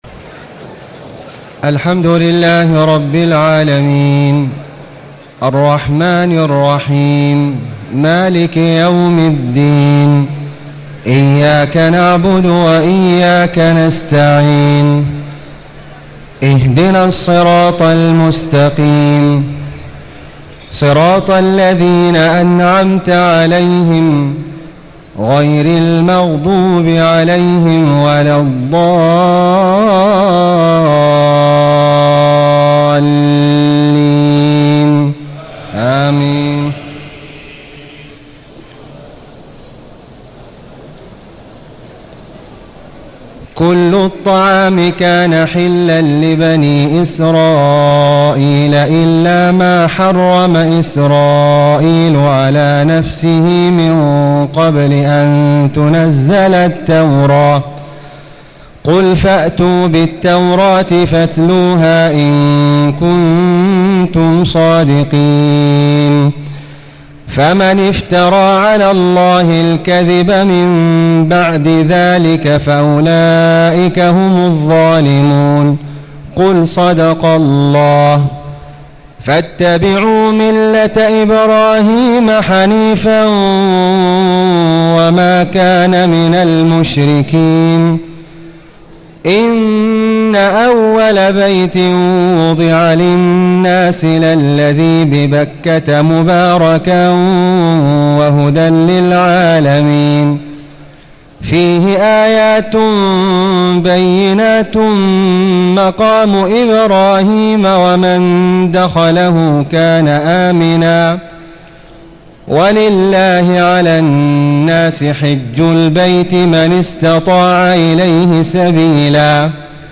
تهجد ليلة 24 رمضان 1436هـ من سورة آل عمران (93-180) Tahajjud 24 st night Ramadan 1436H from Surah Aal-i-Imraan > تراويح الحرم المكي عام 1436 🕋 > التراويح - تلاوات الحرمين